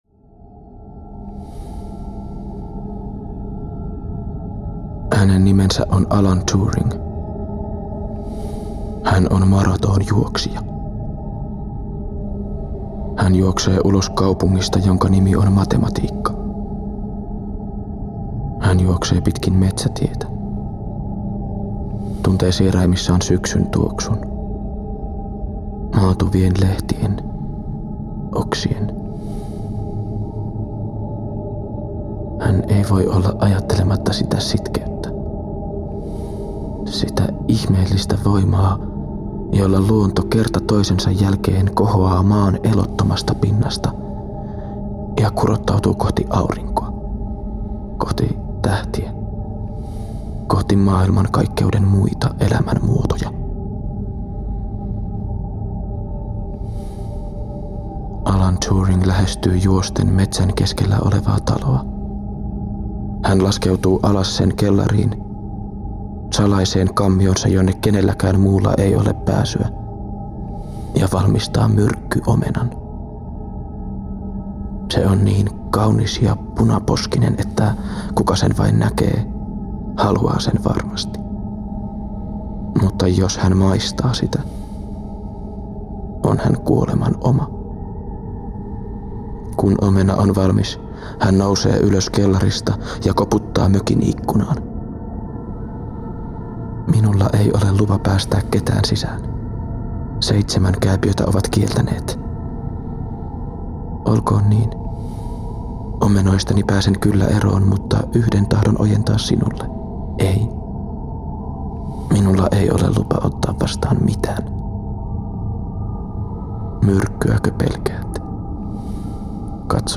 --RADIO DRAMAS--
An audio adaptation of the successful stage production went much further than mere recital of the text and became an independent piece of art. A soothing but profound listening experience.
Musicians: